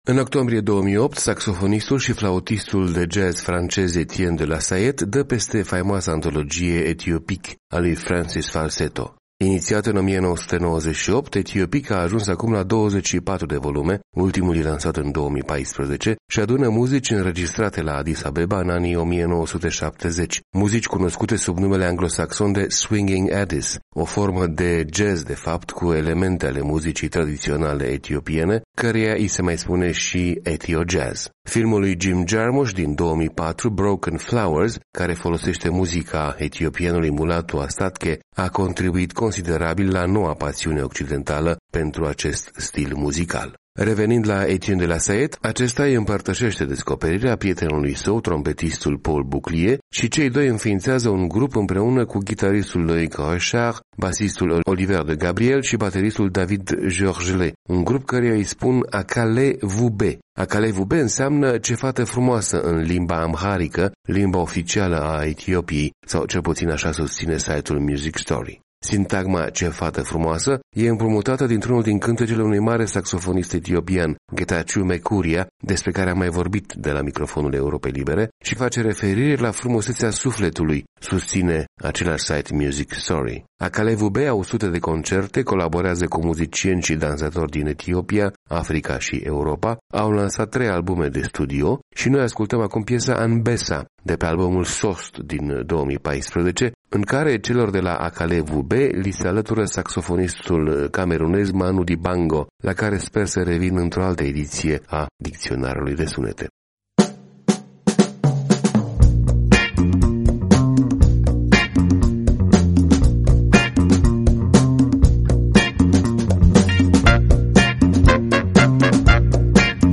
Inițiată în 1998, „Ethiopiques” a ajuns acum la 24 de volume, ultimul e lansat în 2014, și adună muzici înregistrate la Addis Abbeba în anii 1970, cunoscute sub numele anglo-saxon de swingin’Addis, o formă de jazz de fapt cu elemente ale muzicii tradiționale etiopiene, căreia i se mai spune și ethiojazz.
Akalé Wubé au sute de concerte, colaborează cu muzicieni și dansatori din Etiopia, Africa și Europa, an lansat trei albume de studio, și noi ascultăm acum piesa „Anbessa” de pe albumul „Sost” din 2014, în care celor de la Akalé Wubé li se alătură sxaofonistul camerunez Manu Dibango, la care sper sp revin într-o altă ediție a Dicționarului de Sunete